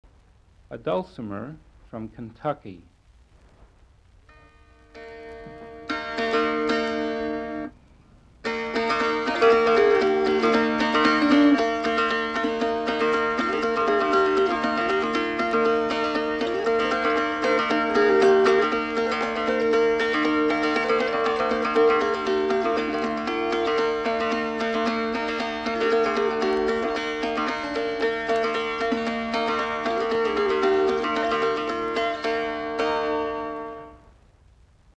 14. A Dulcimer from Kentucky